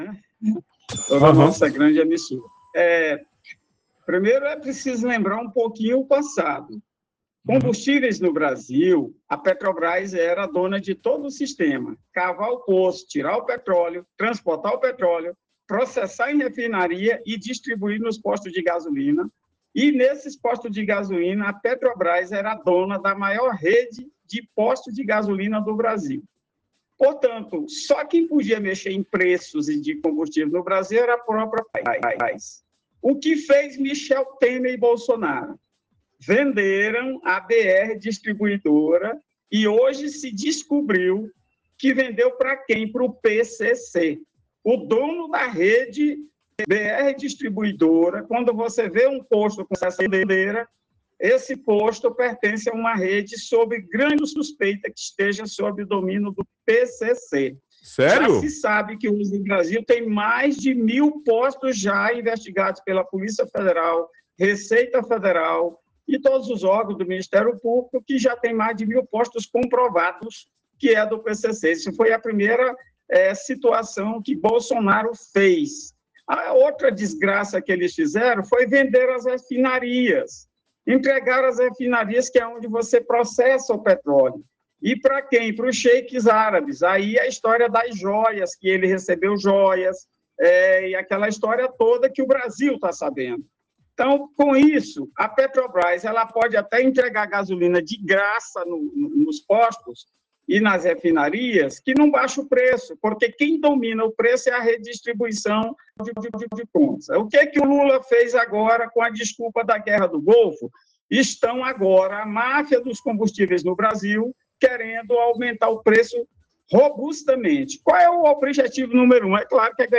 Durante entrevista ao programa Sorriso Show, na rádio Avalanche FM, ex-deputado disse que investigações federais apontam atuação de organizações criminosas no setor de distribuição de combustíveis.
Entrevista-Siba-Machado.ogg